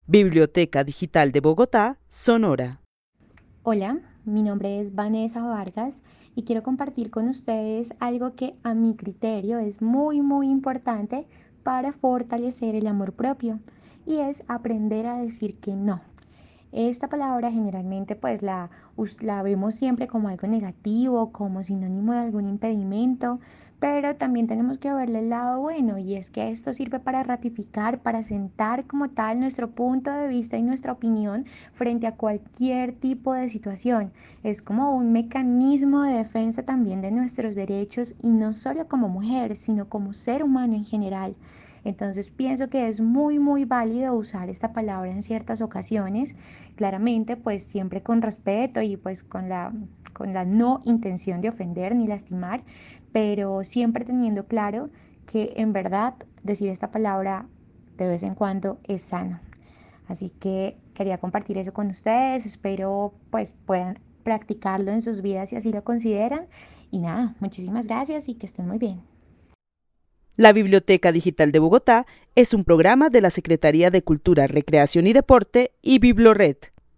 Narración oral de una mujer de la ciudad de Bogotá. En su relato comparte un ejercicio de fortalecimiento para el amor propio que consta de aprender a decir que no, y así sentar una posición frente a las diversas situaciones de la vida.
El testimonio fue recolectado en el marco del laboratorio de co-creación "Postales sonoras: mujeres escuchando mujeres" de la línea Cultura Digital e Innovación de la Red Distrital de Bibliotecas Públicas de Bogotá - BibloRed.